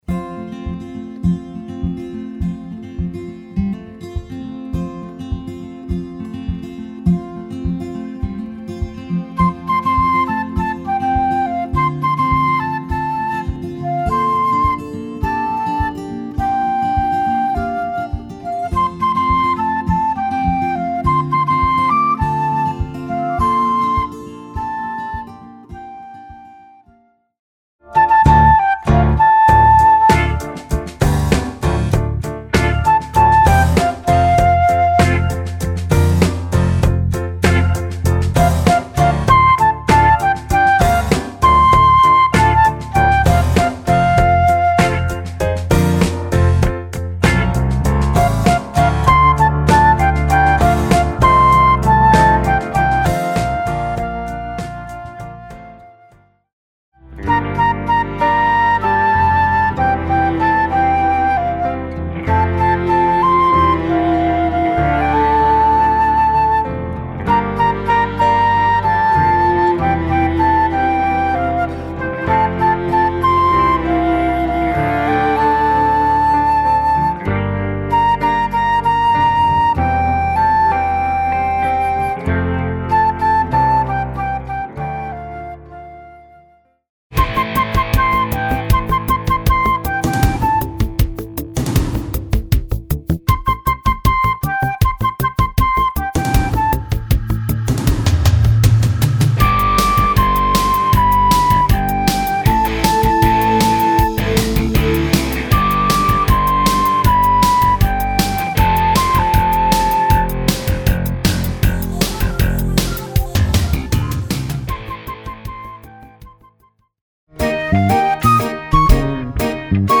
Voicing: Flute